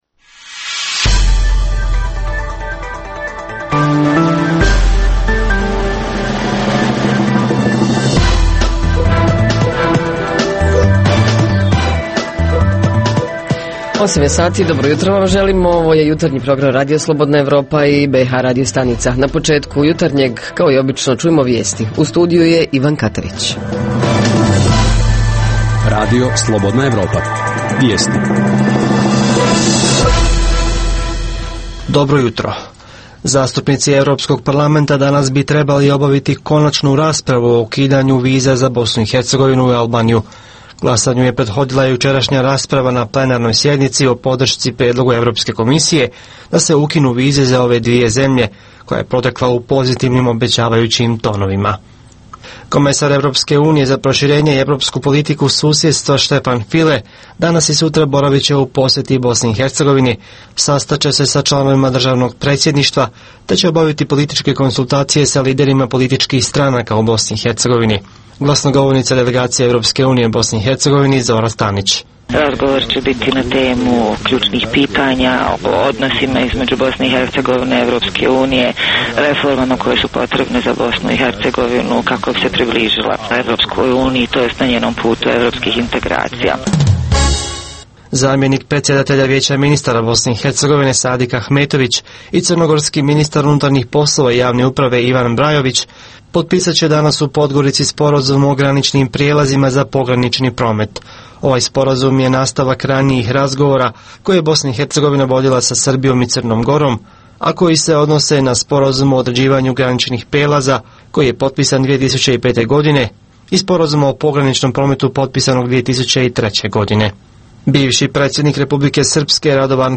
- Redovni sadržaji jutarnjeg programa za BiH su i vijesti i muzika.